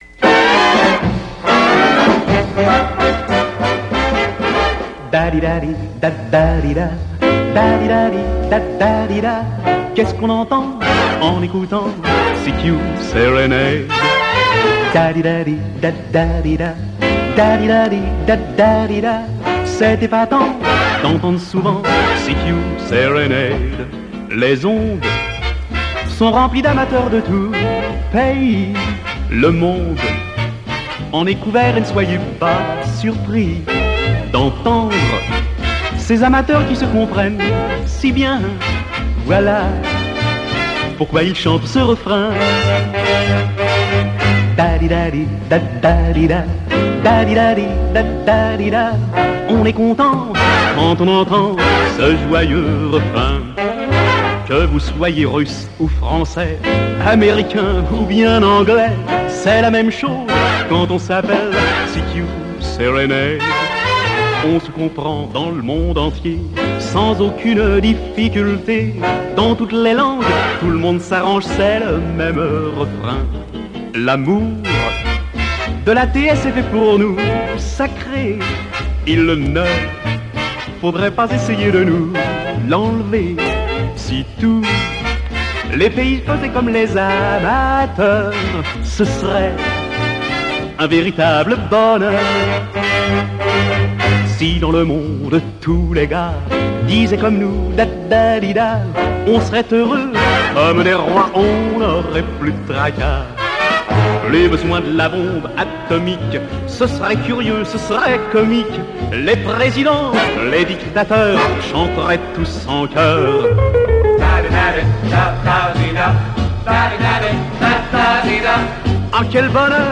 Une belle chanson